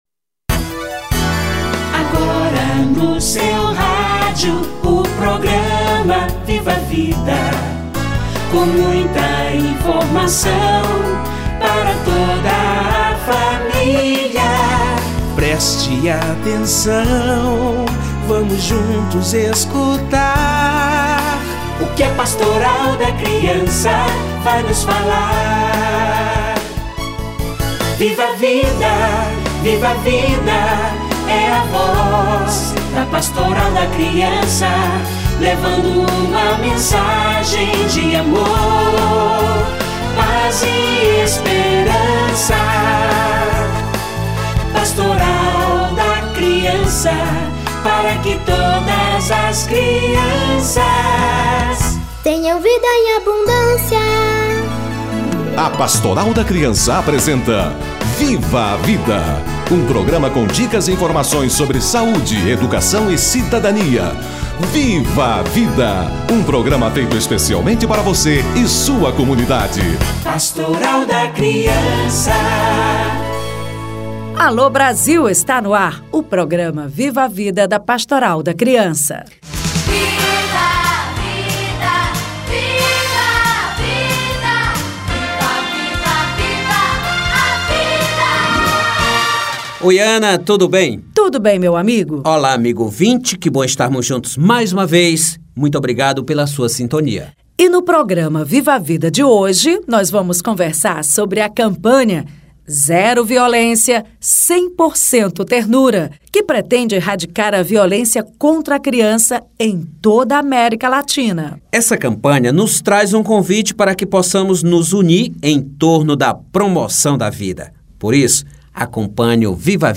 Identificação de sinais de maus tratos ou de violência - Entrevista